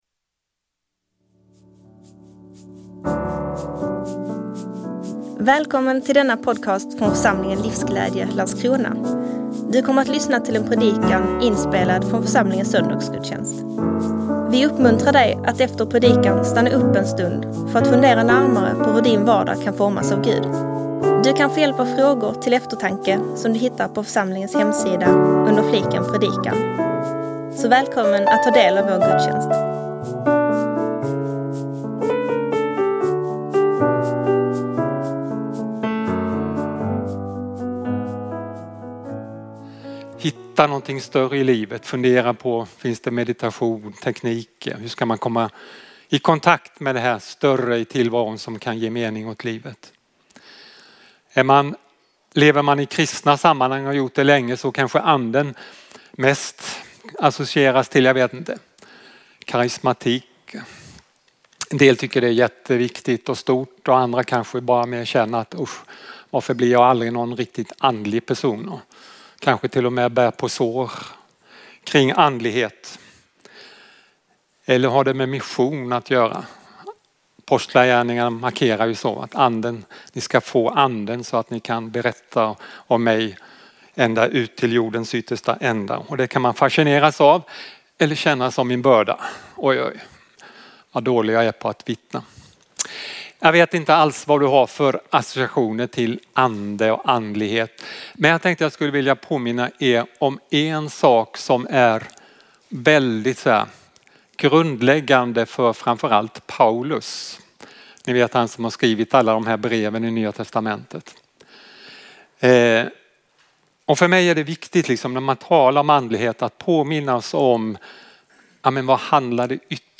26 maj 2024 Anden och andlighet Predikant